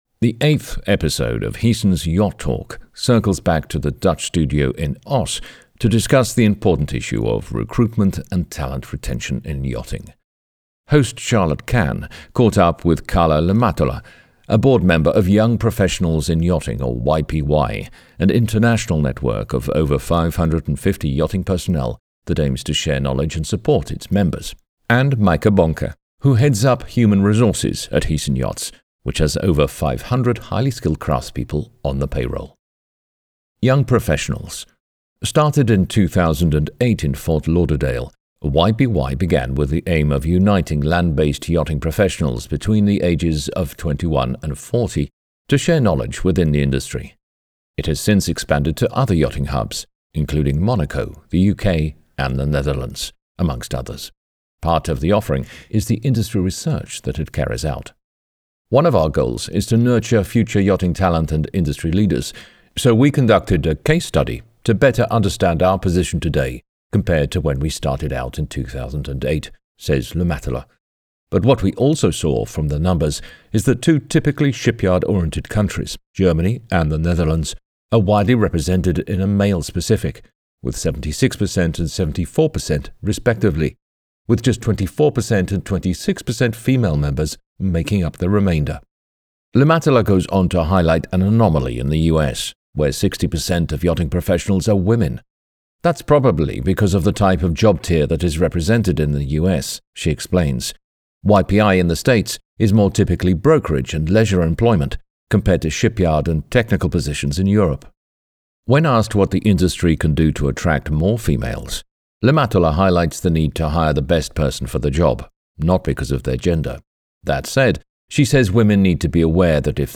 When asked why they enjoy working for the yachting industry, the panellists responded with the following: